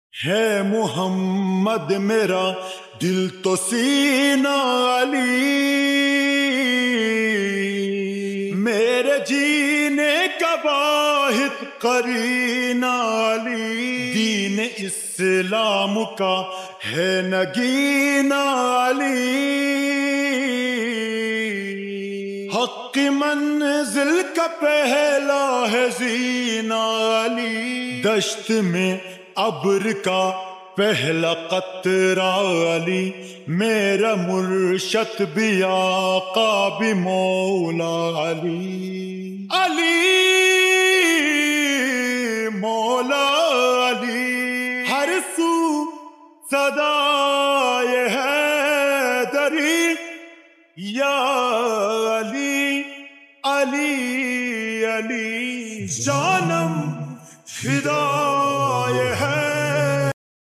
NAAT STATUS